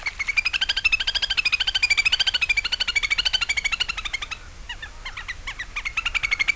trillo ( 284 KB ) squillante ed inconfondibile.